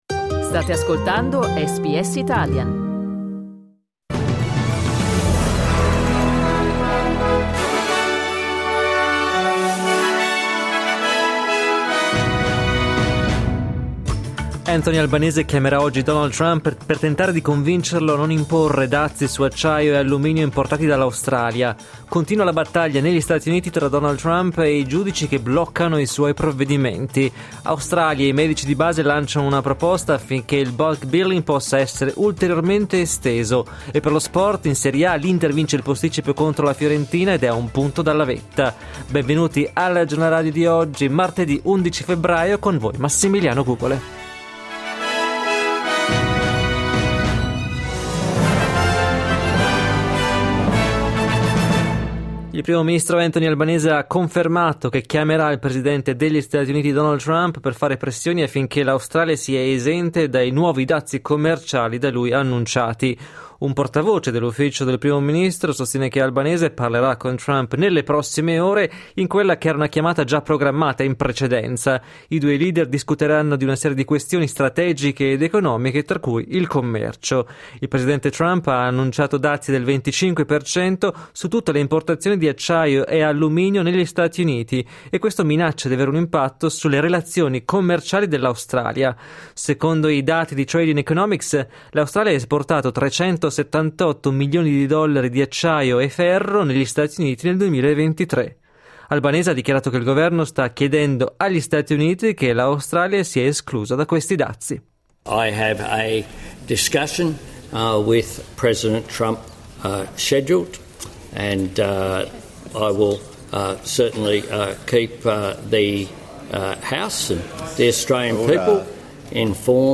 Giornale radio martedì 11 febbraio 2025 | SBS Italian
Il notiziario di SBS in italiano.